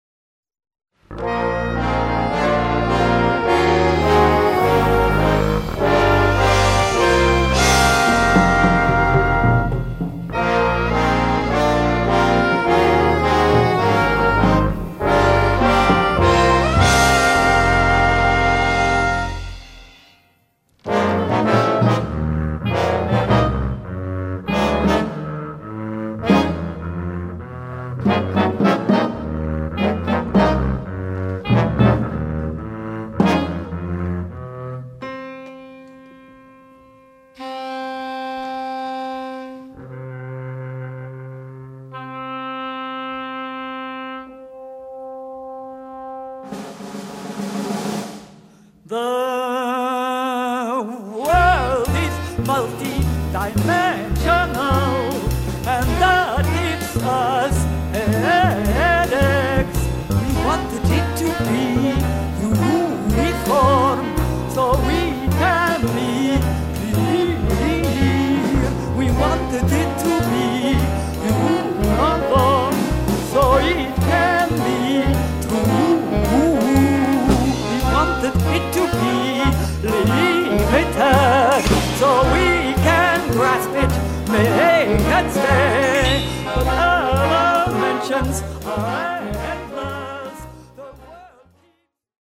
oboa, angleški rog, bas klarinet, flavte
tenorski in sopranski saksofon
klavir, dirigiranje
kontrabas
bobni, tolkala
francoski rog
glas